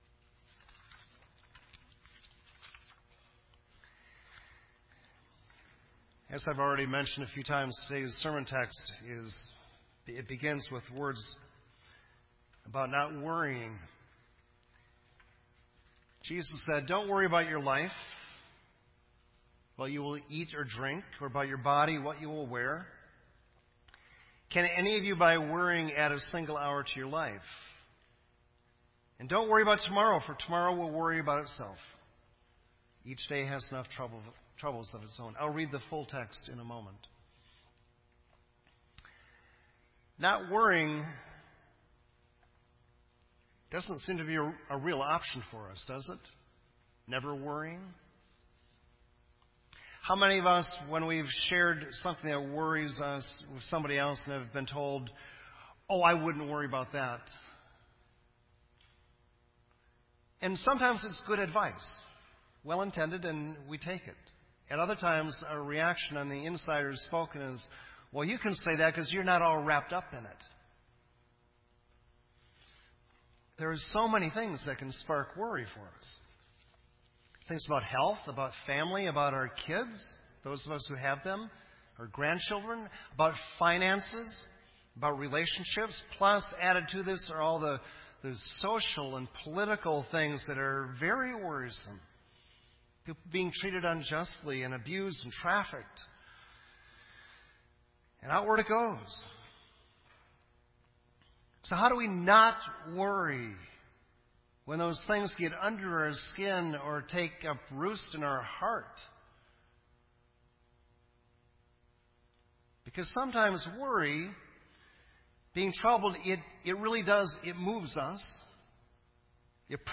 This entry was posted in Sermon Audio on February 20